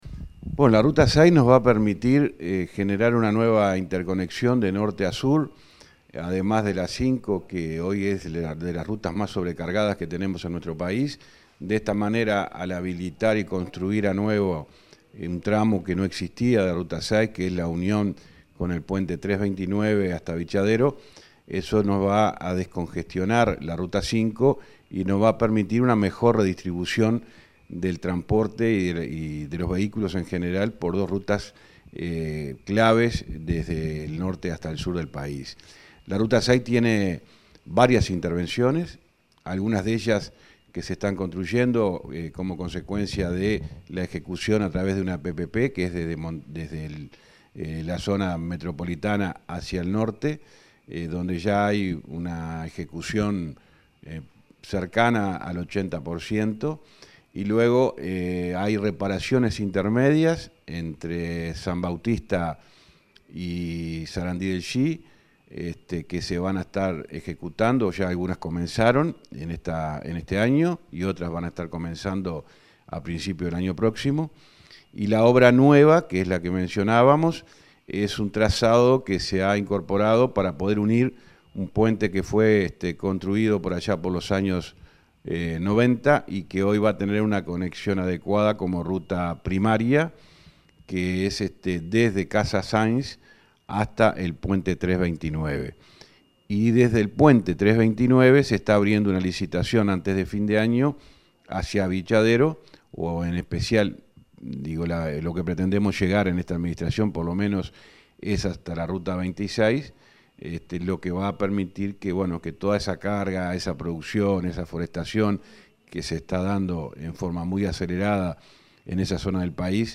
Entrevista al ministro de Transporte, José Luis Falero
El ministro de Transporte y Obras Públicas, José Luis Falero, dialogó con Comunicación Presidencial acerca del avance de obras en ruta n.° 6.